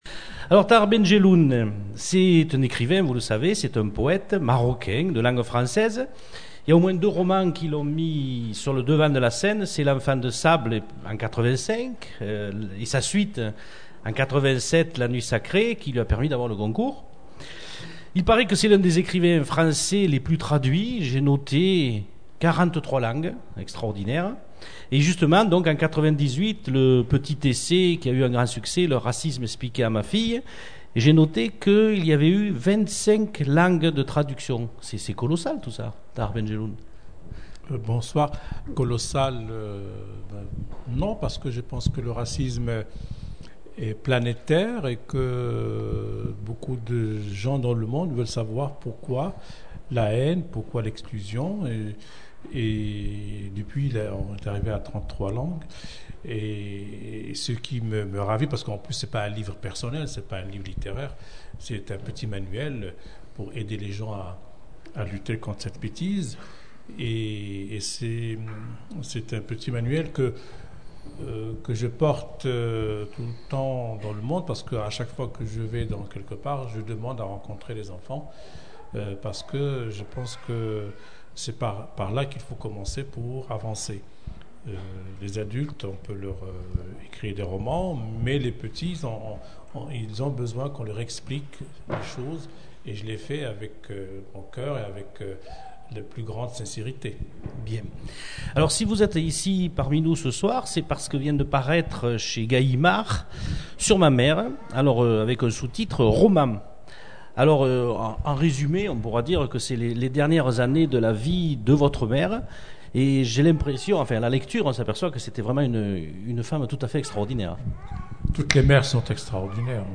Rencontre avec Tahar Ben Jelloun
Ben Jelloun, Tahar. Personne interviewée